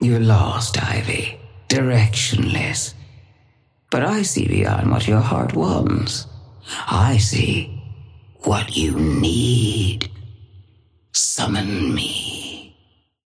Sapphire Flame voice line - You're lost, Ivy.
Patron_female_ally_tengu_start_01_alt_02.mp3